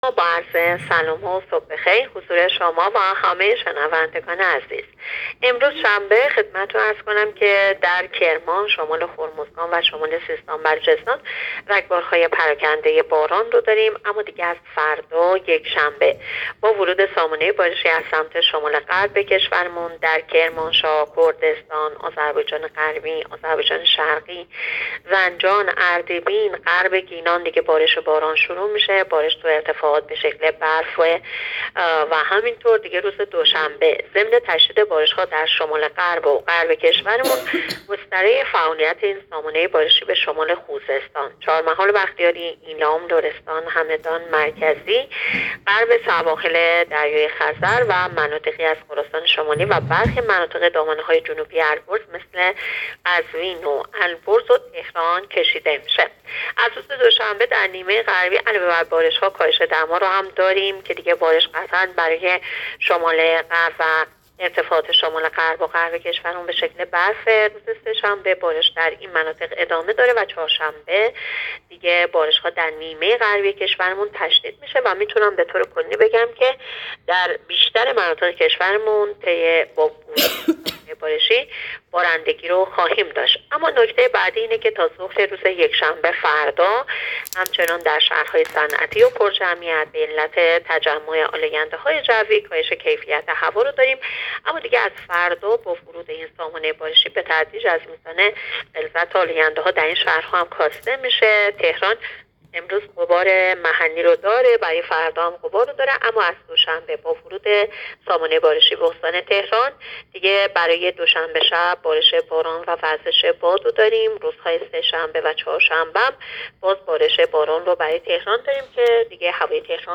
گزارش رادیو اینترنتی پایگاه‌ خبری از آخرین وضعیت آب‌وهوای ۱۵ آذر؛